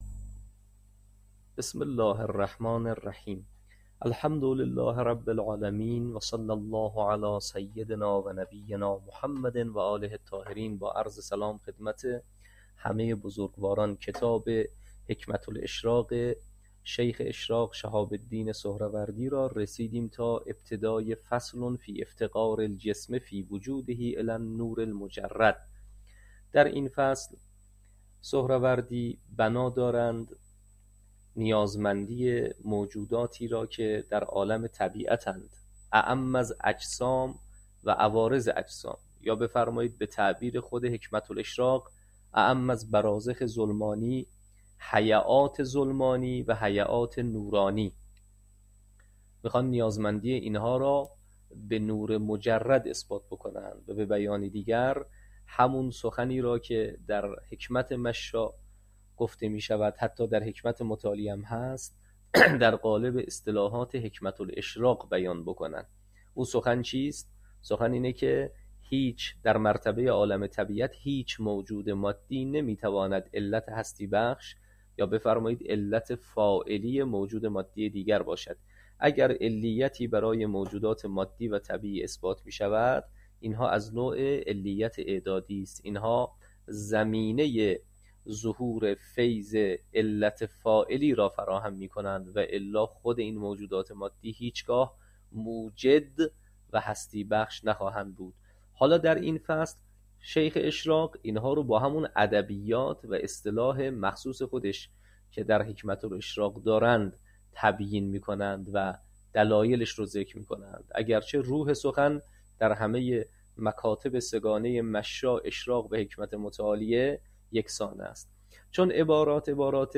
حکمه الاشراق - تدریس